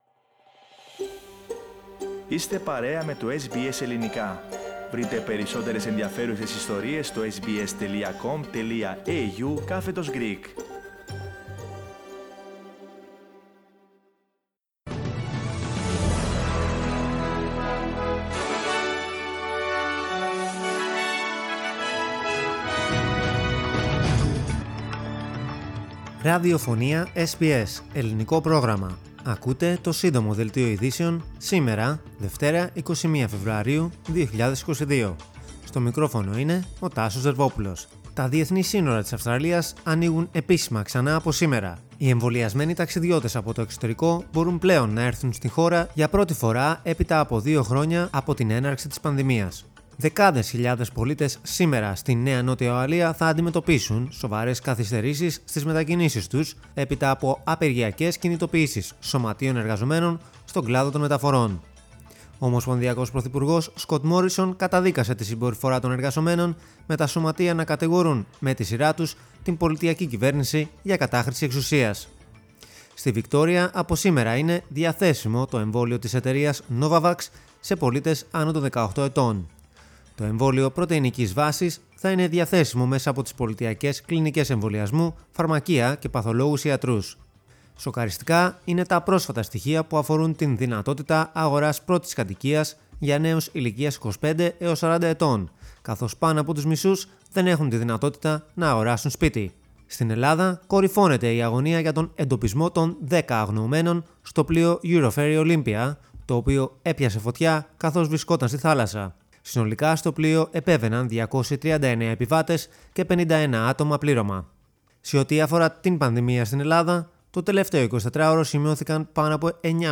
News in Greek. Source: SBS Radio